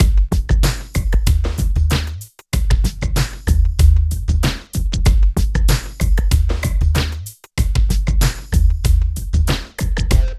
62 DRUM LP-R.wav